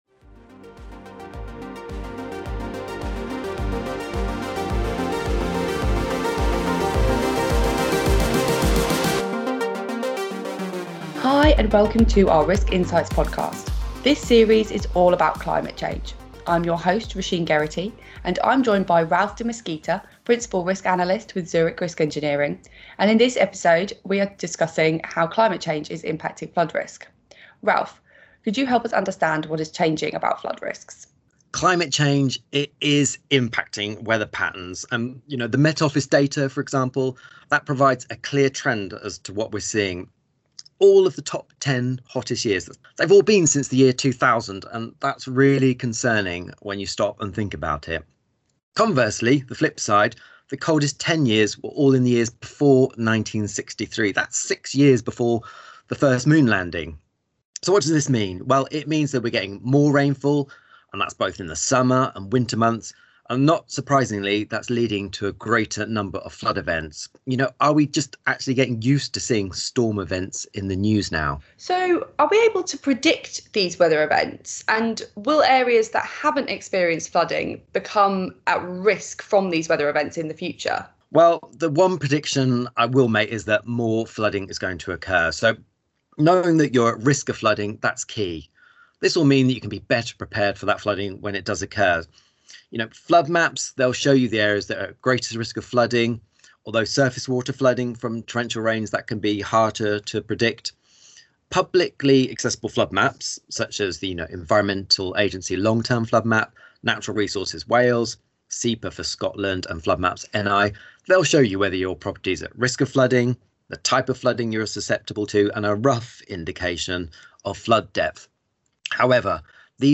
Our new brand purpose is to create a brighter future for all, so in this next series of podcasts we interview experts from across the Zurich business and explore how climate change is impacting risk management practices and how organisations can prepare for these fast changing risks.